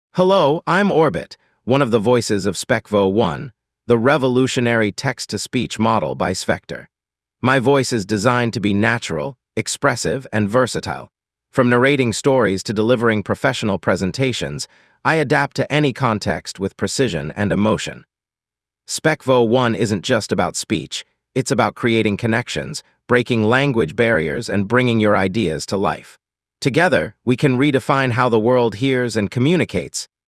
Spec-Vo1 is a revolutionary text-to-speech model, combining human-like expressiveness, multilingual capabilities, and cutting-edge AI for unparalleled audio synthesis.
With two distinct voices, Orbit (male) and Swin (female), Spec-Vo1 brings unmatched versatility to your projects, empowering developers and creators to deliver authentic audio experiences.
• Human-Like Voices: Two distinct voices—Orbit and Swin—designed for expressive and natural speech output.
Orbit Voice Sample